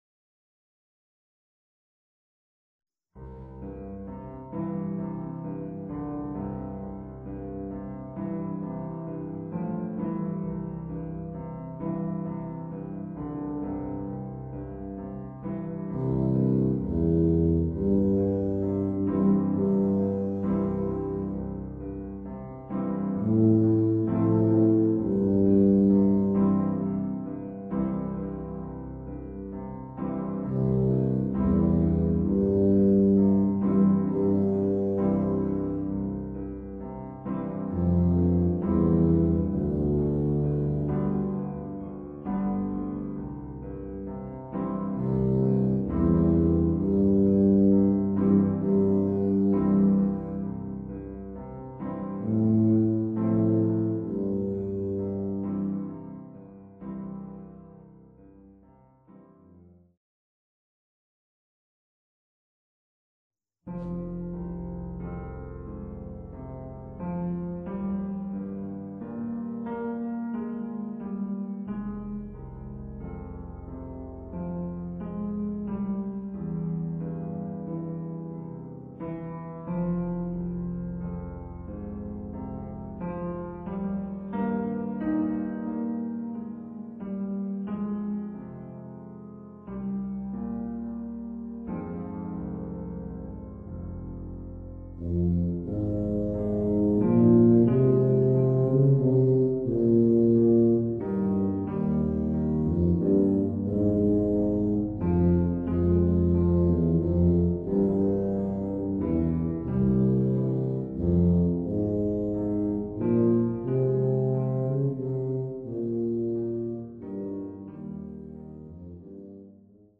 Voicing: Euphonium w/ Audio